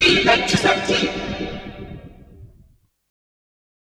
VVE1 Vocoder Phrases 15.wav